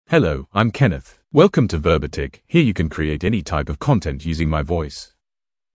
MaleEnglish (United Kingdom)
Kenneth is a male AI voice for English (United Kingdom).
Voice sample
Listen to Kenneth's male English voice.
Kenneth delivers clear pronunciation with authentic United Kingdom English intonation, making your content sound professionally produced.